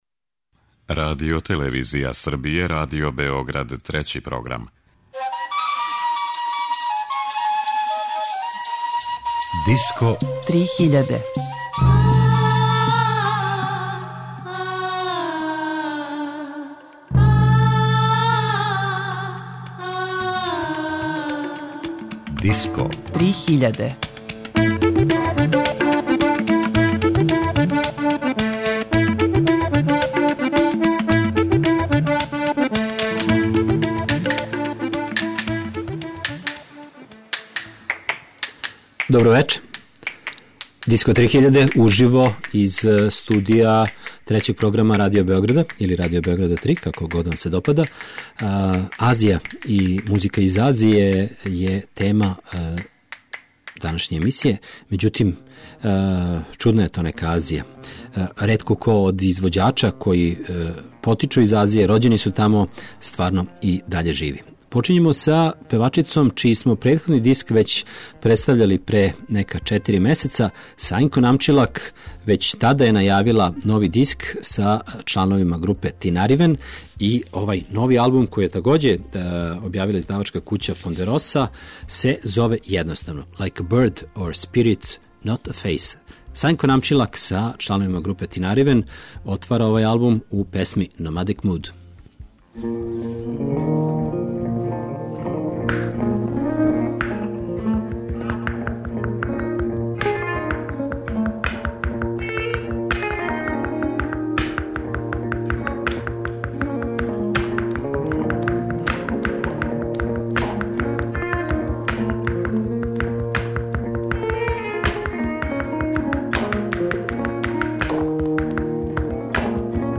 Не тако често у овој емисији можете чути музику из Азије.
Индијски блуз.